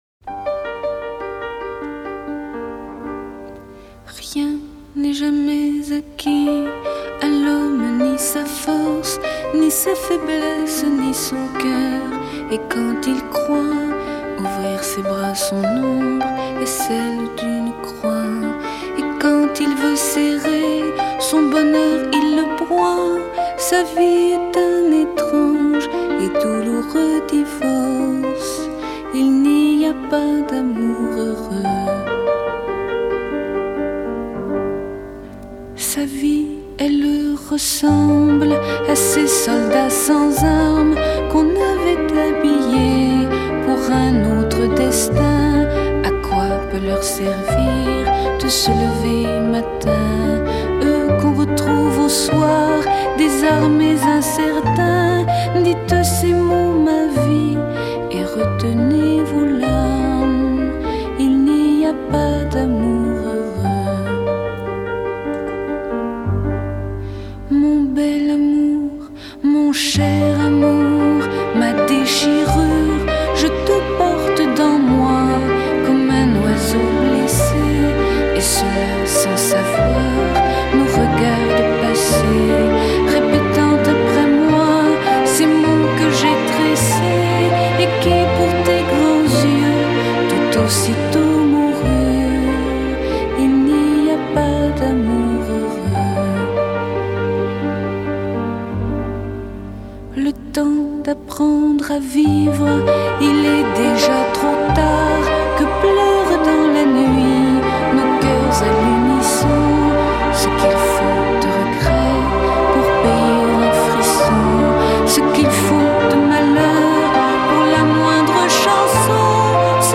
虽有无尽的感伤，
却无法抵挡那感伤背后的宁静……
轻柔的钢琴伴奏，